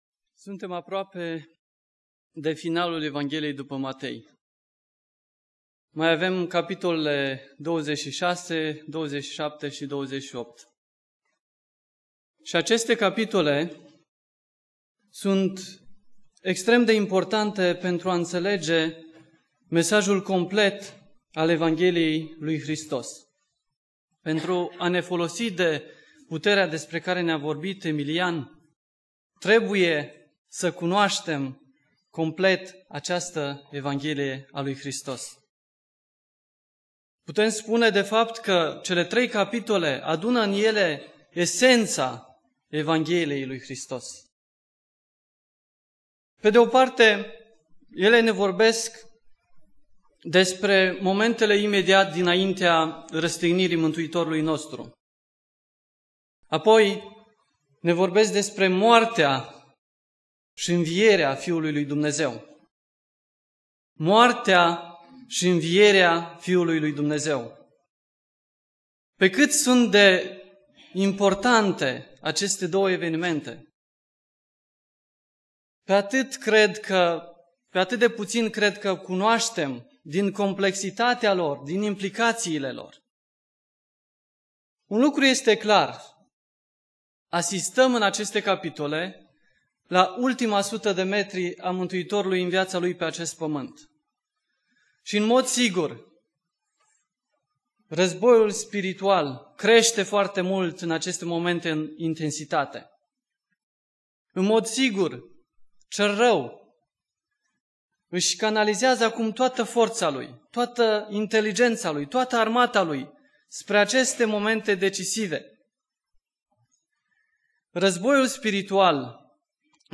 Predica Exegeza - Matei 26